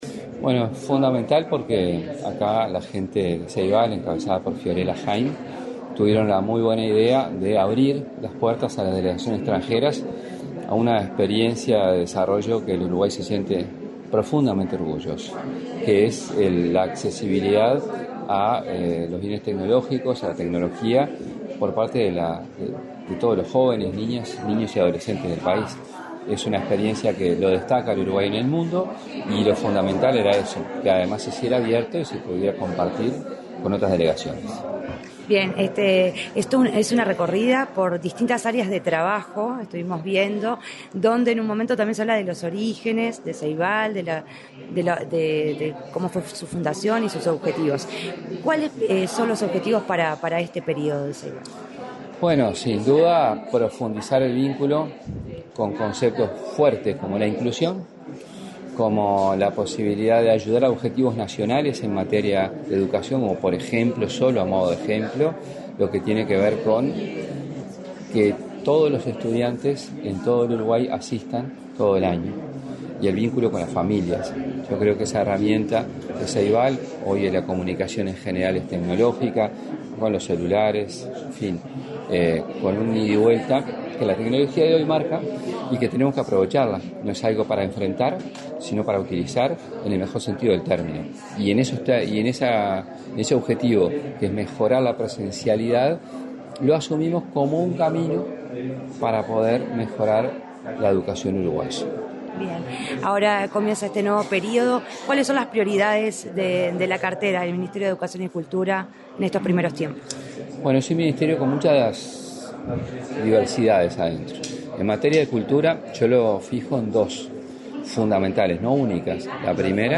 Declaraciones del ministro de Educación y Cultura, José Carlos Mahía
Declaraciones del ministro de Educación y Cultura, José Carlos Mahía 02/03/2025 Compartir Facebook X Copiar enlace WhatsApp LinkedIn El ministro de Educación y Cultura, José Carlos Mahía, dialogó con la Presidencia de la República, durante la recorrida que realizó junto con visitantes extranjeros por las instalaciones de Ceibal.